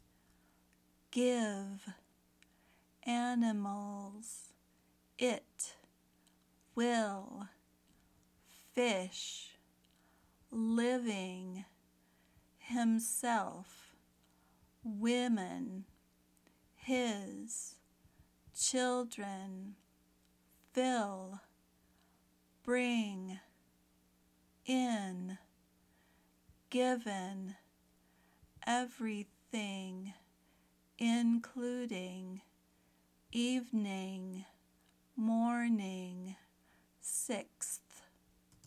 Practice the Short I Sound
short-i.mp3